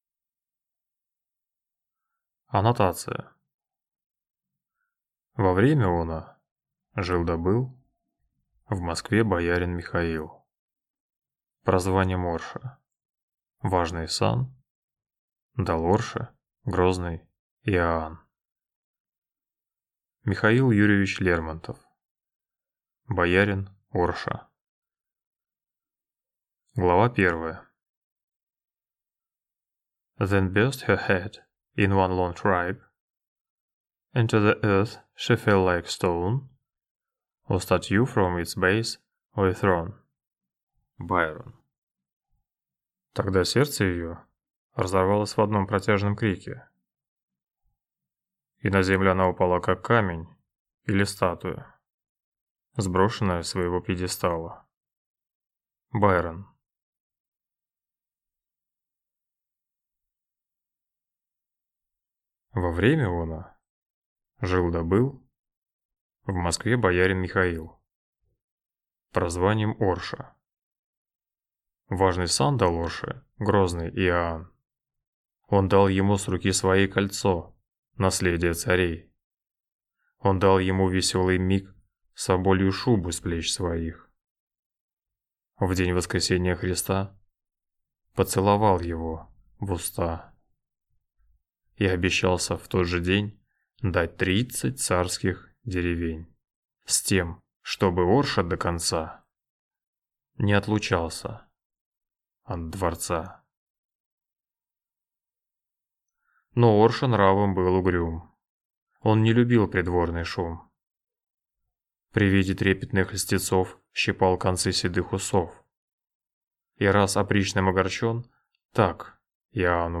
Аудиокнига Боярин Орша